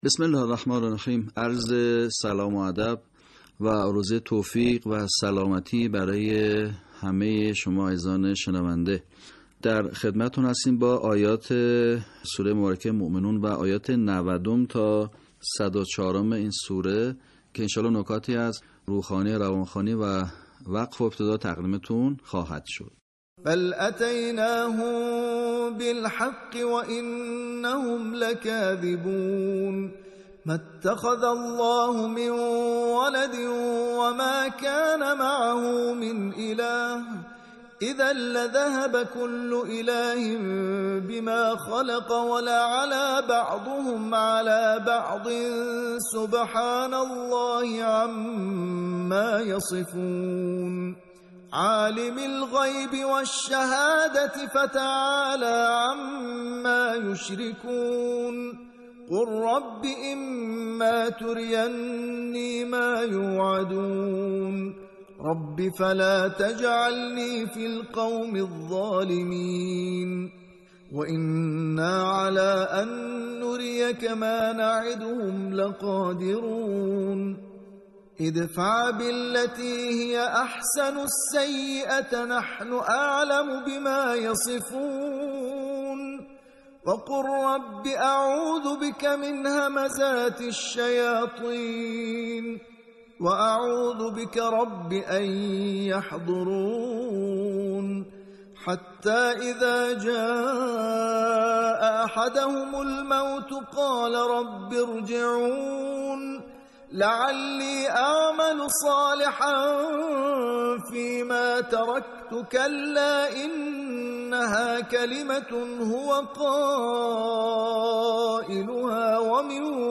صوت | آموزش صحیح‌خوانی آیات ۹۰ تا ۱۰۴ سوره مؤمنون
به همین منظور مجموعه آموزشی شنیداری (صوتی) قرآنی را گردآوری و برای علاقه‌مندان بازنشر می‌کند.